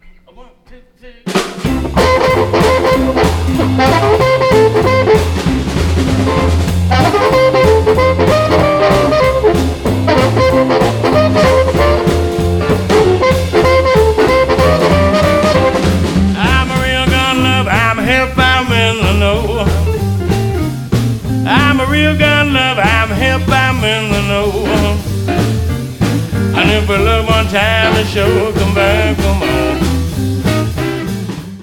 It's a fast paced show